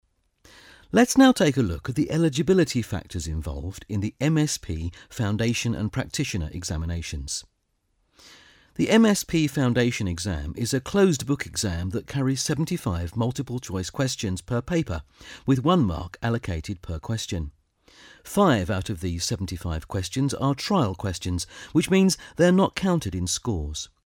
British English voiceover, mature voice, caring, authoritative.
Sprechprobe: eLearning (Muttersprache):
British English voiceover for commercials, documentaries, corporate videos and e-learning.